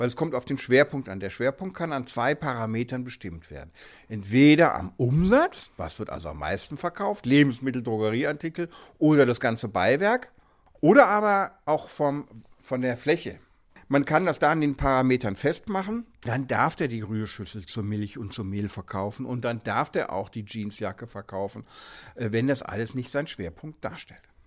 O-Ton: Was darf wer während der Pandemie verkaufen?